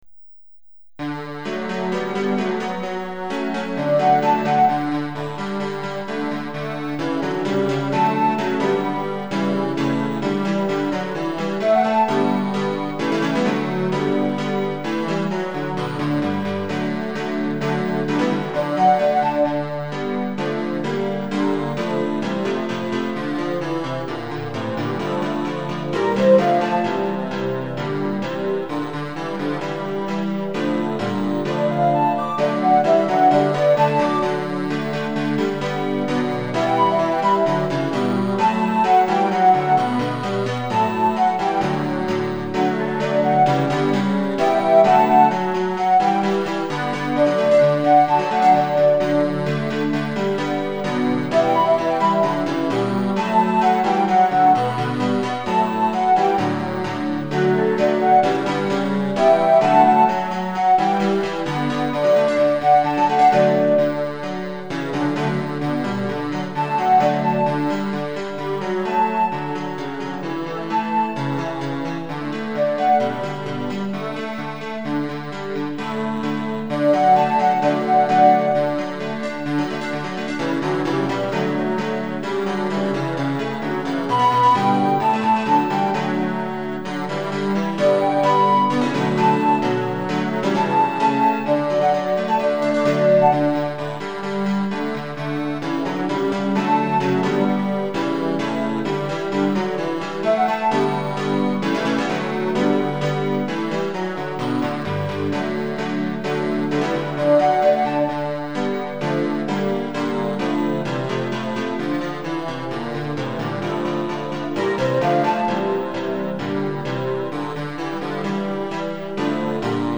〜カラオケ版〜